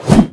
shovelswing.ogg